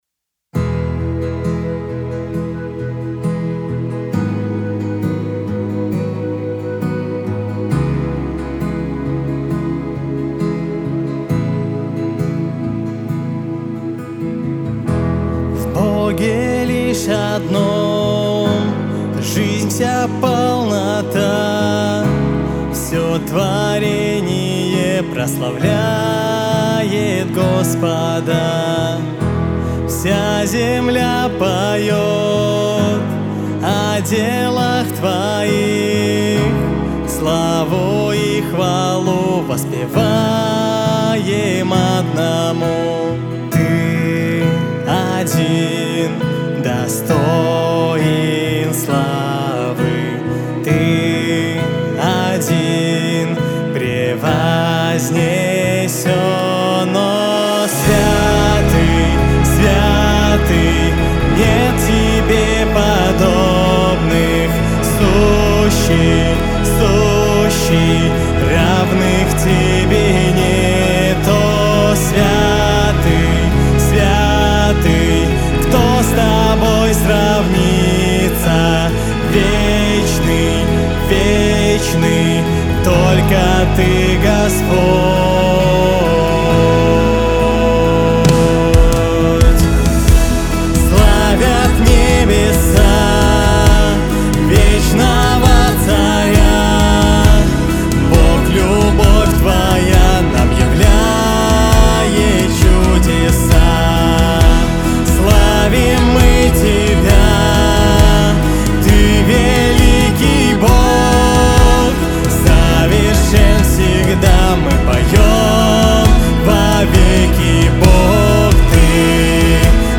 132 просмотра 130 прослушиваний 10 скачиваний BPM: 67